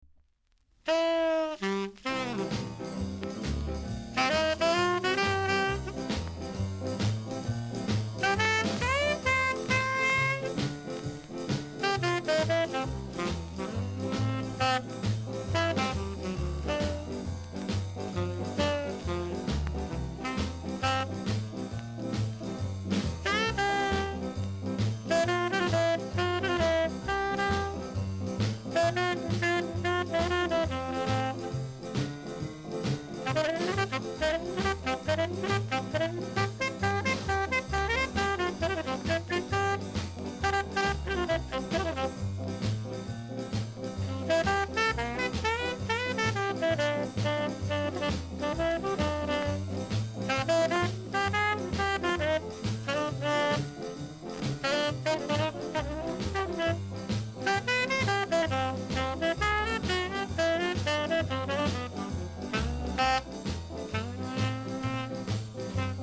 ※最後に一箇所傷(修正痕あり)あり、少しプチパチあります。